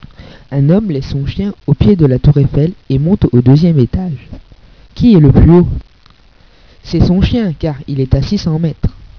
Nos blagues :